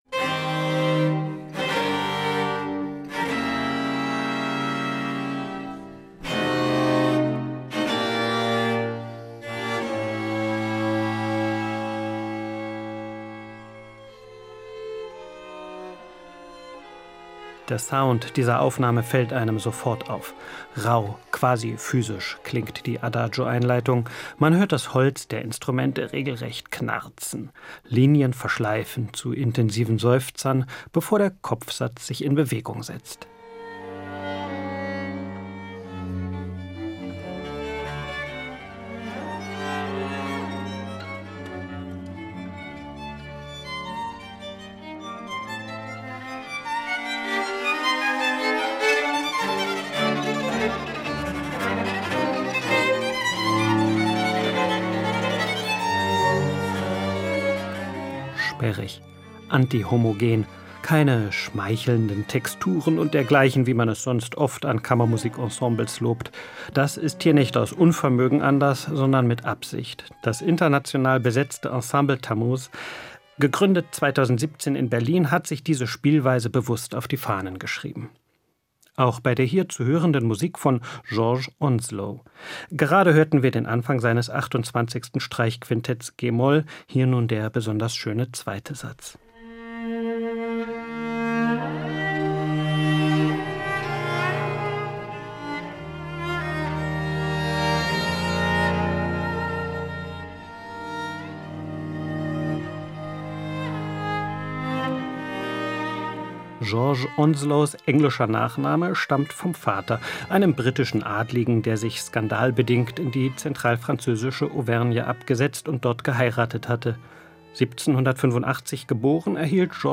Album-Tipp
Sperrig, anti-„homogen“. Keine „schmeichelnden Texturen“ und dergleichen, wie man es sonst oft an Kammermusik-Ensembles lobt.
Beide Quintette sind tief besetzt, also nicht mit doppelter Bratsche, sondern mit zwei Celli.